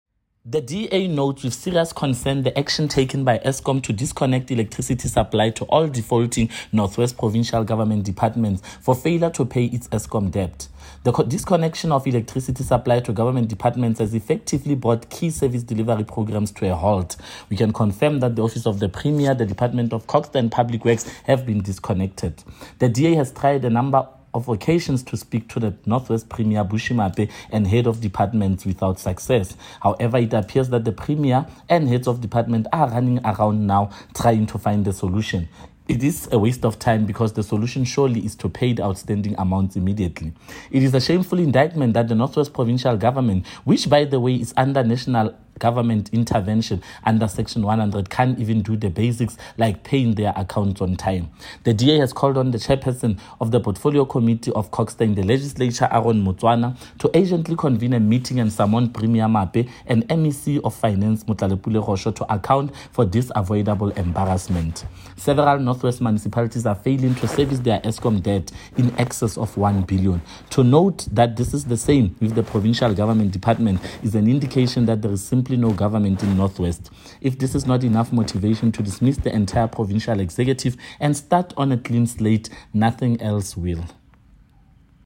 Note to Editors: Please find attached a soundbite in
English by Freddy Sonakile MPL.